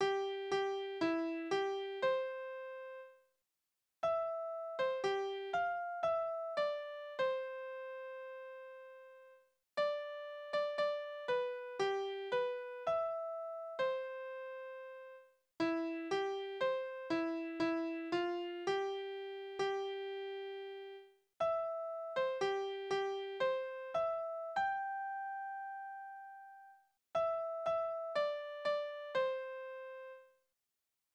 Gib, o gib dein Herz Liebeslieder: Liebes 1x1 1x1 ist 1.